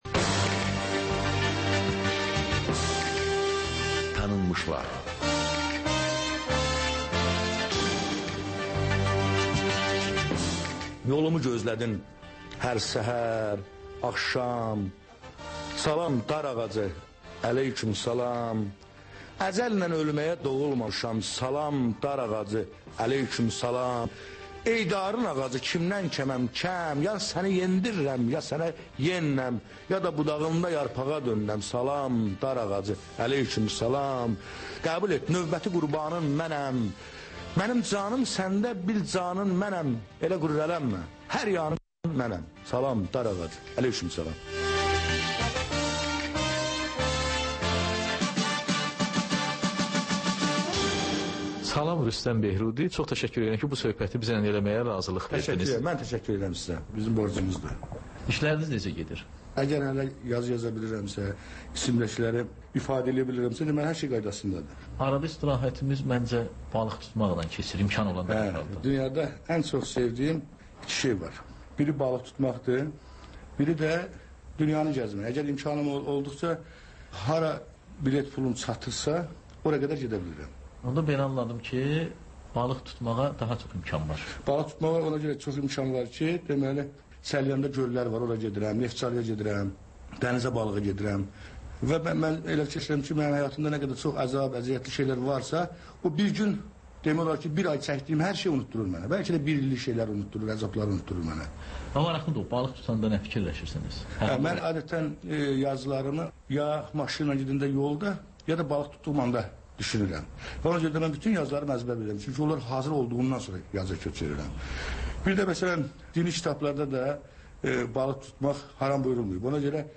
Ölkənin tanınmış simalarıyla söhbət